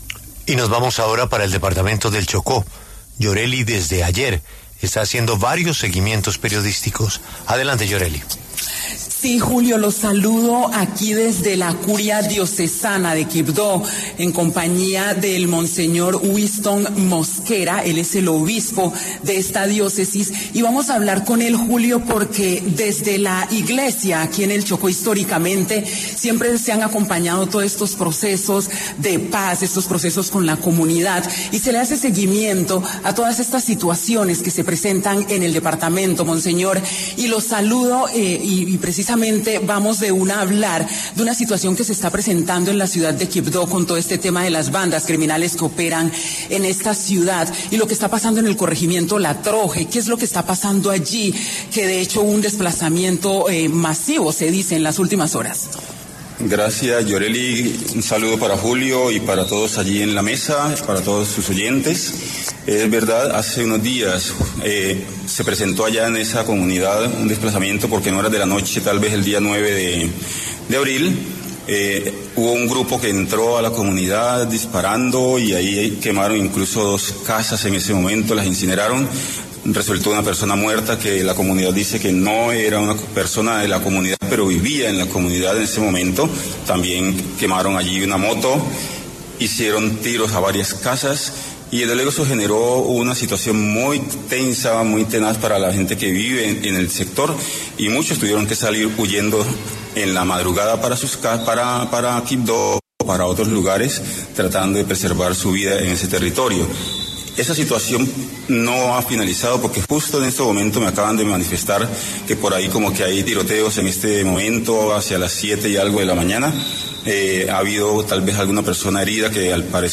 Monseñor Winston Mosquera, obispo de la diócesis de Quibdó, se pronunció en La W sobre la crisis de orden público en la región.